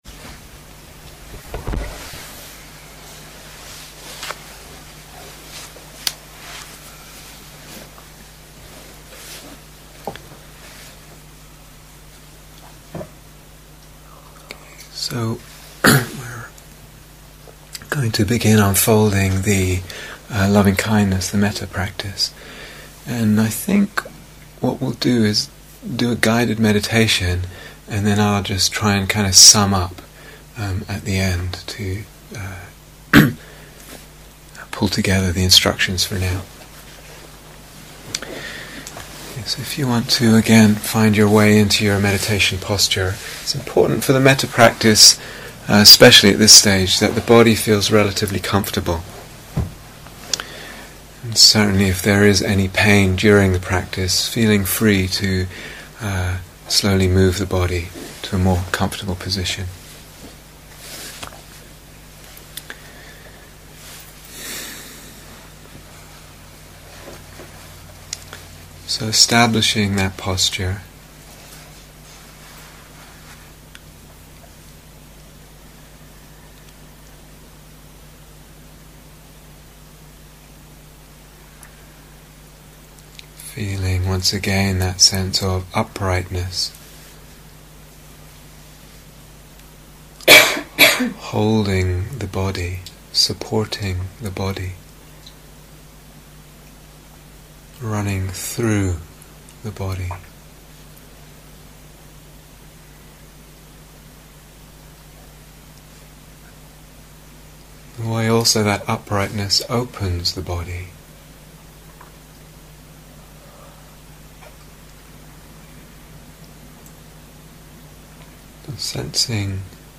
First Instructions and Guided Mettā Meditation